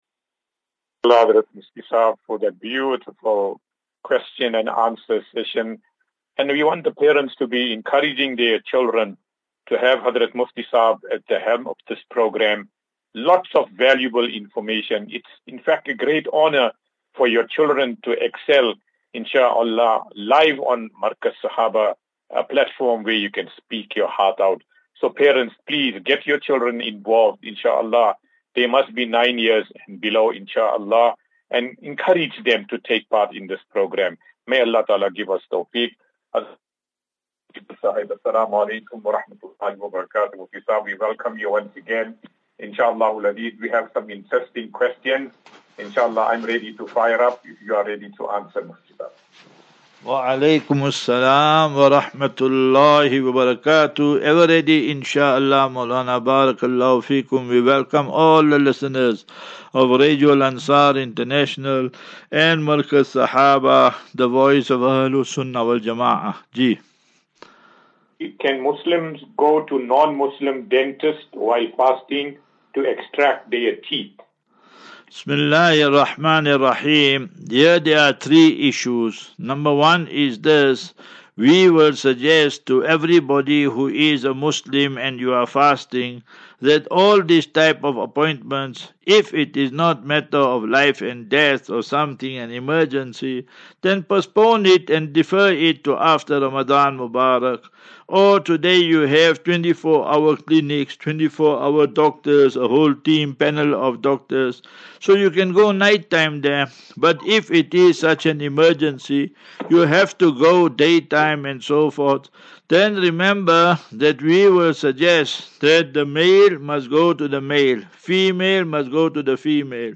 As Safinatu Ilal Jannah Naseeha and Q and A 15 Mar 15 March 2024.